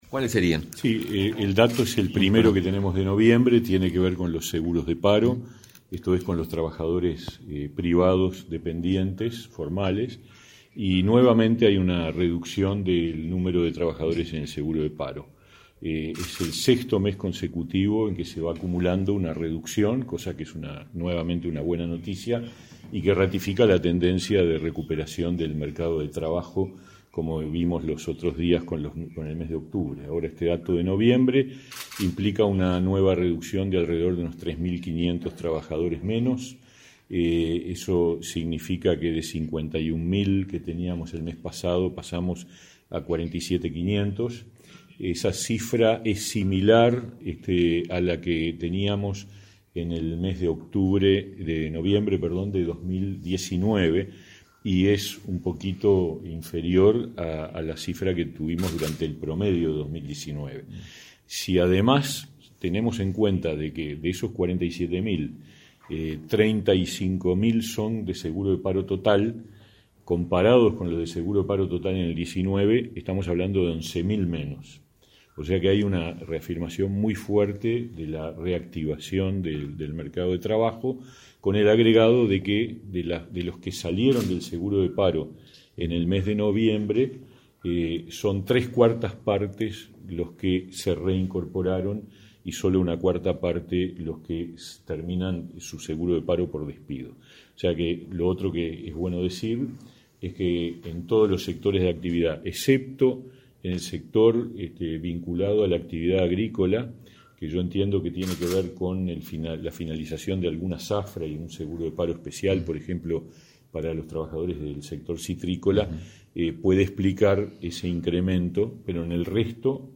Conferencia de prensa del ministro de Trabajo, Pablo Mieres